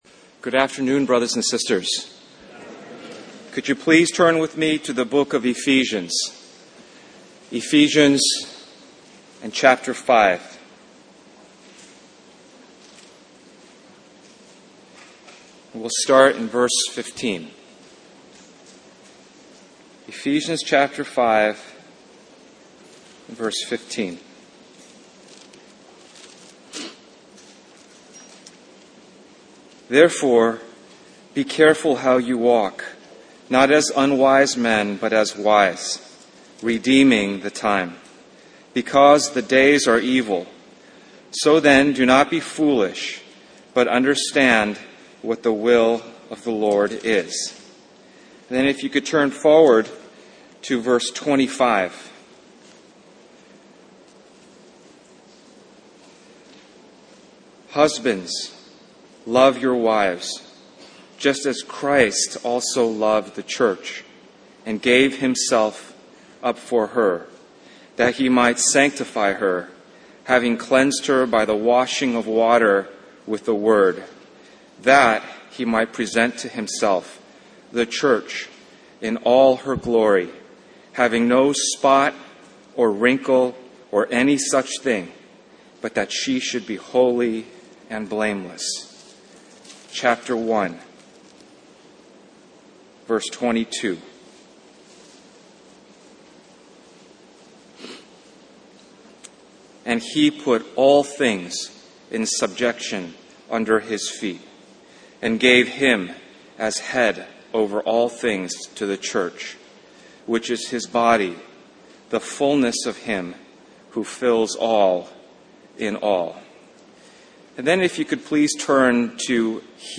Harvey Cedars Conference We apologize for the poor quality audio